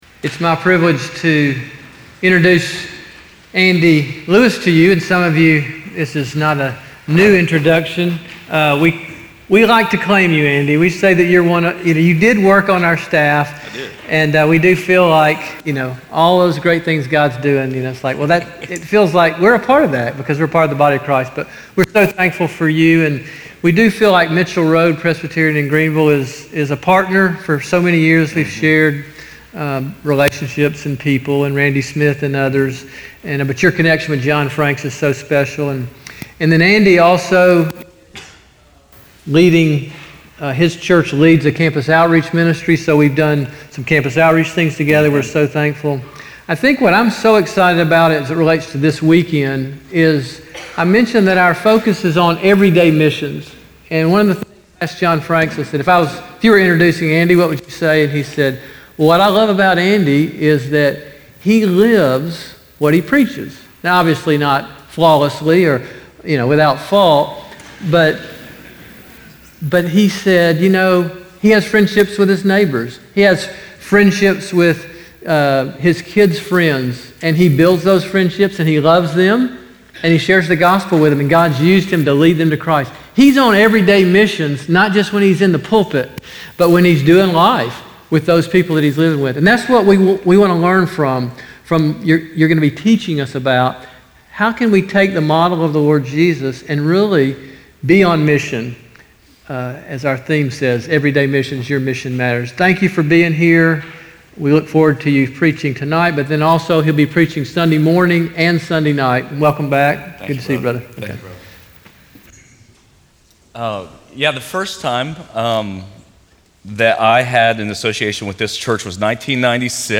Message: "Banquet Message" from Guest Speaker - First Presbyterian Church of Augusta